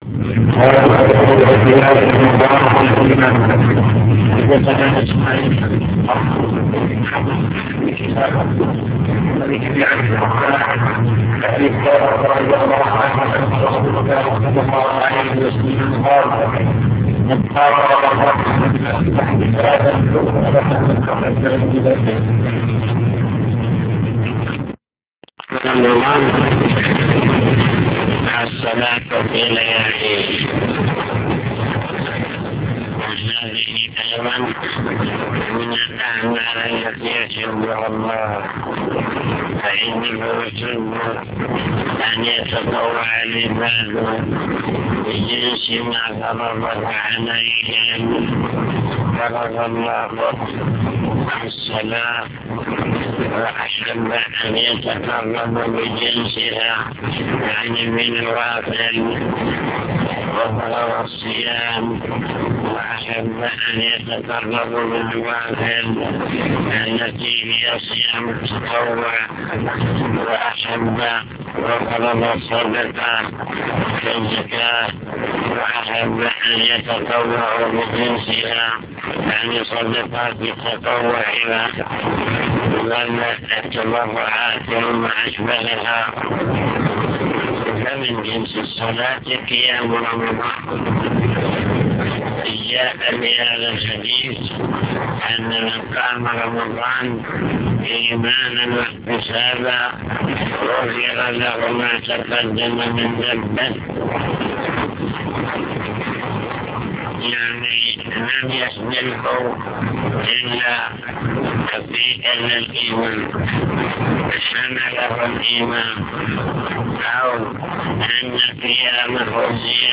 المكتبة الصوتية  تسجيلات - كتب  شرح كتاب الإيمان من صحيح البخاري